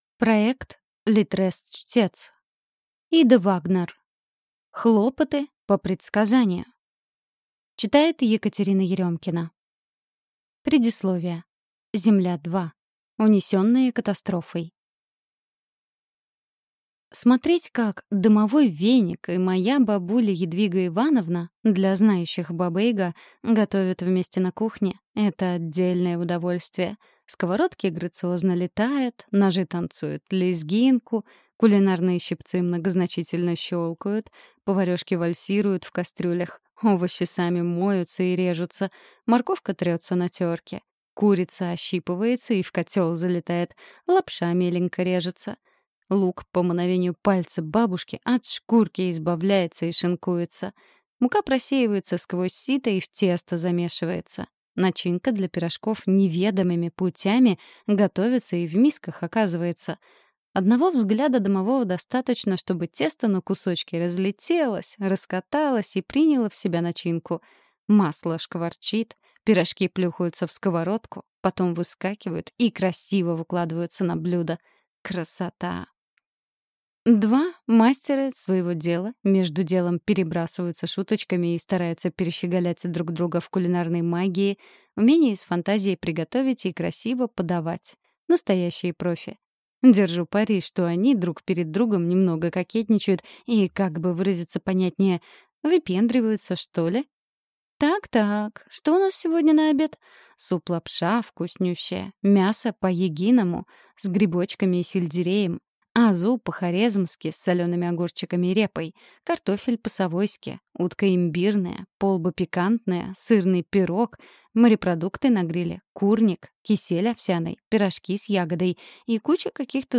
Аудиокнига Хлопоты по предсказанию | Библиотека аудиокниг